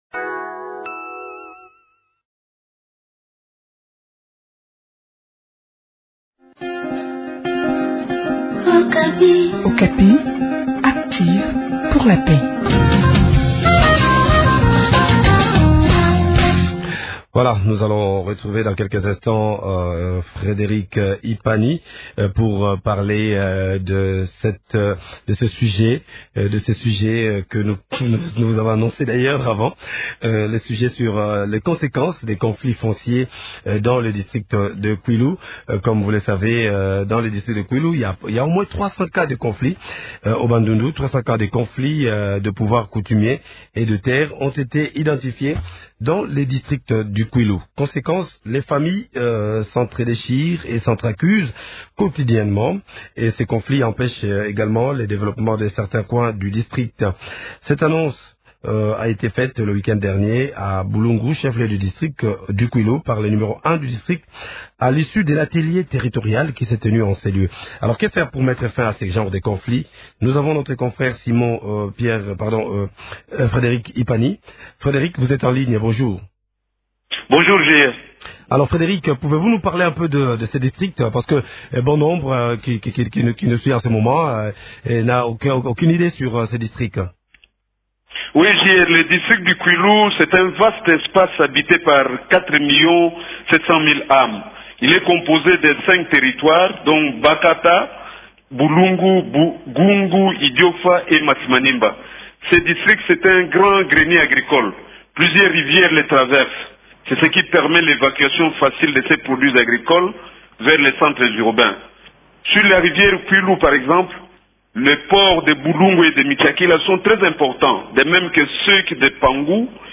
en parle avec Simon Pierre Tshibuj, Commissaire de district du Kwilu